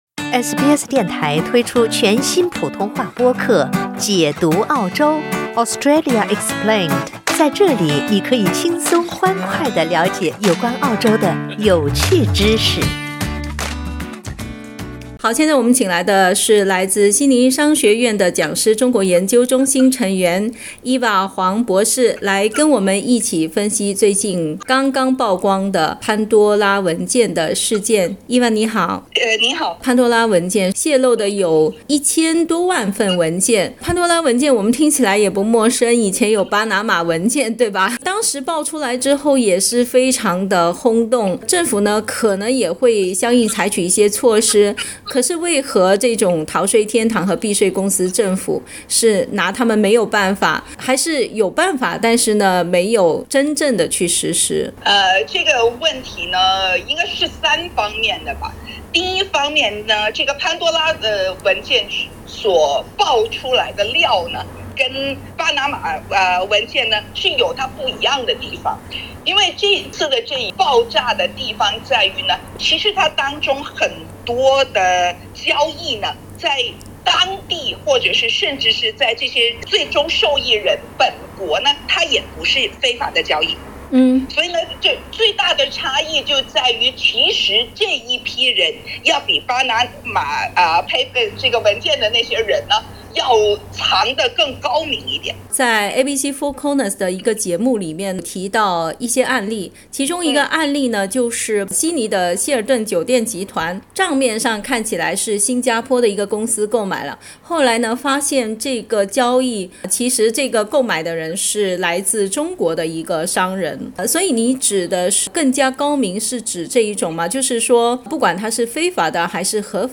（點擊上圖收聽寀訪） 本節目為嘉賓觀點，不代表本台立場 澳大利亞人必鬚與他人保持至少1.5米的社交距離，請查看您所在州或領地的最新社交限制措施。